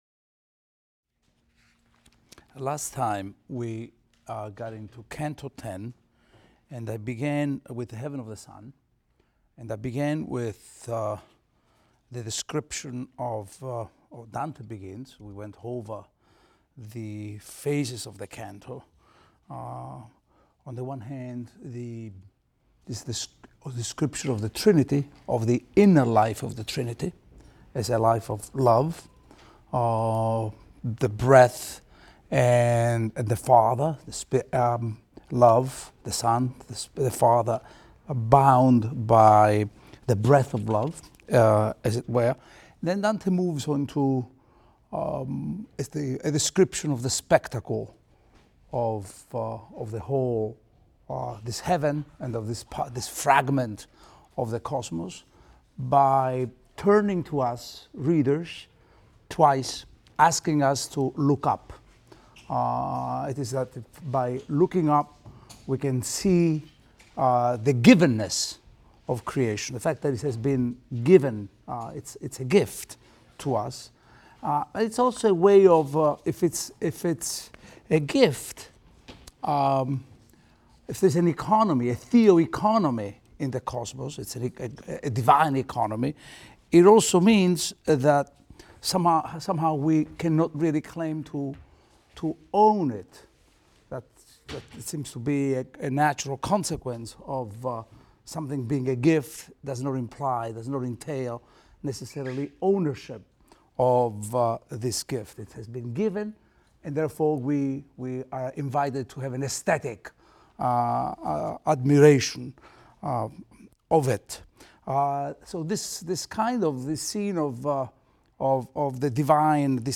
ITAL 310 - Lecture 18 - Paradise XI, XII | Open Yale Courses